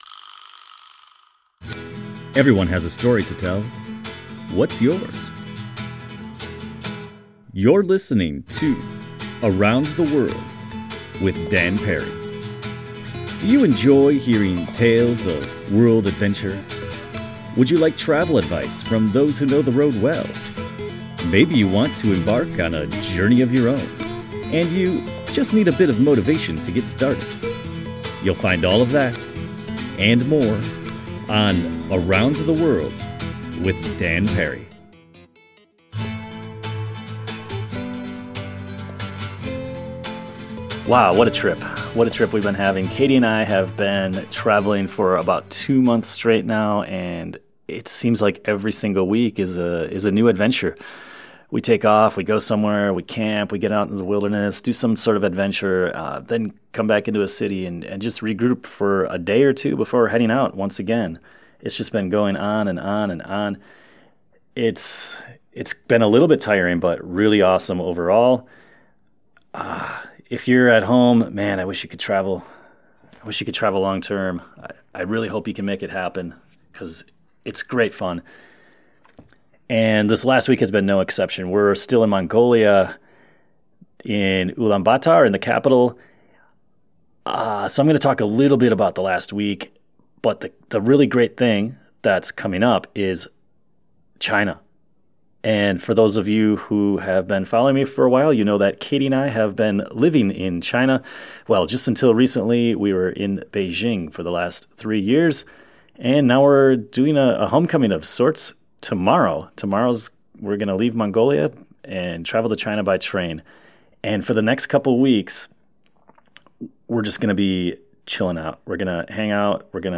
We recorded our conversation from the top of a sand dune in the Gobi desert in Mongolia.